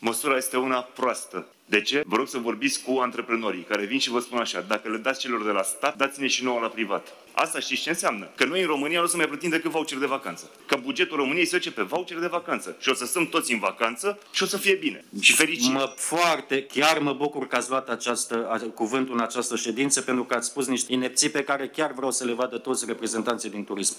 Deputatul PNL Dan Vîlceanu a atras atenția că și companiile private își vor dori, pe viitor, să acorde vouchere de vacanță din banii statului și nu din fondurile proprii, ca în prezent: